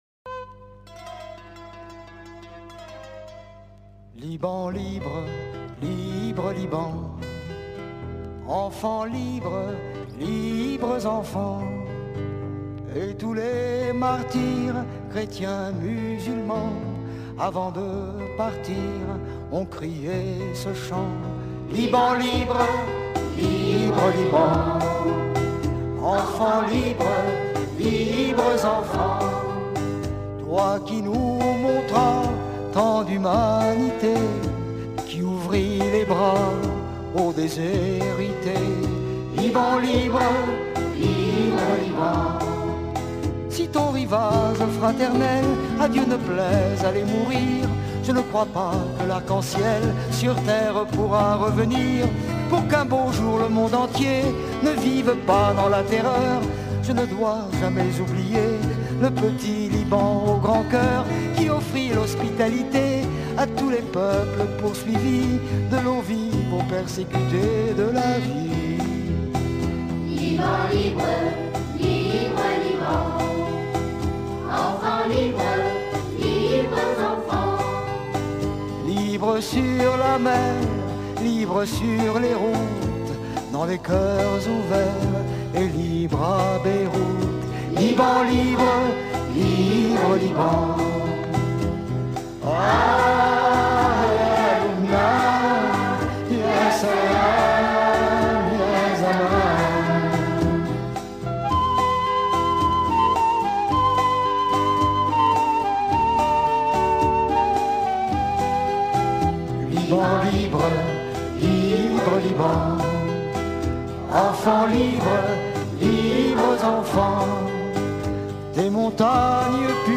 Sur un fond de musique bien méditerranéen